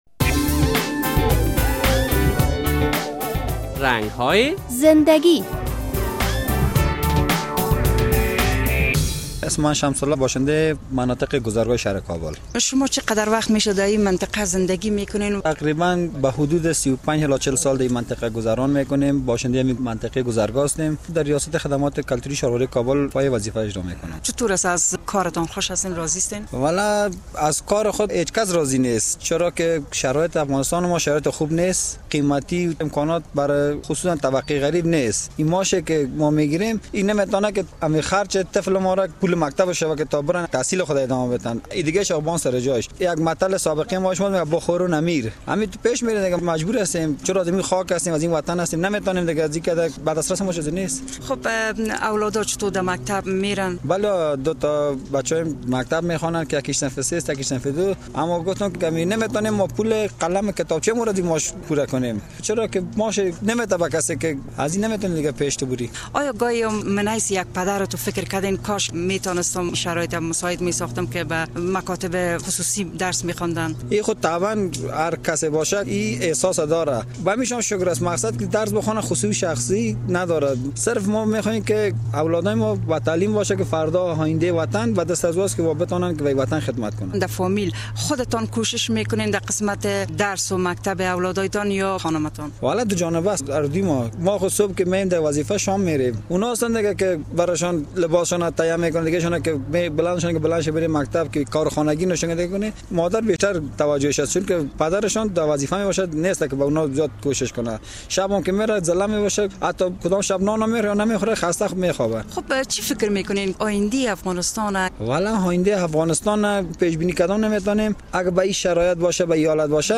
گزرگاه یکی از مناطق شهر کابل است، در این برنامه با یکی از باشنده گان این منطقه مصاحبه کرده ایم و در مورد آینده اطفال و کشورش از او پرسش های داشتیم، به پاسخ های این پدر افغان گوش دهید: